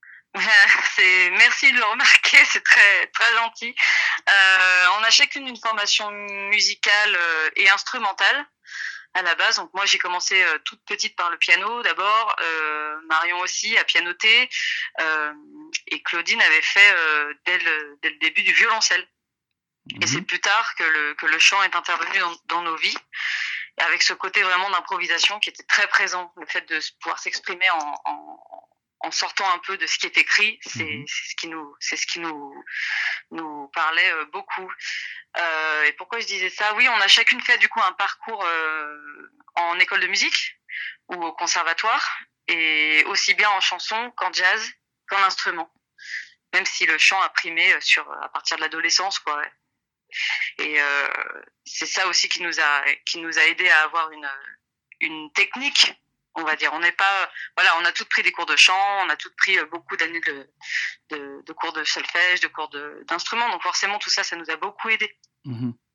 Cette fois, c’est le groupe Glossy Sisters, sélectionné dans la catégorie Jazz / World / Soul, qui se prête au jeu des questions/réponses.
L’interview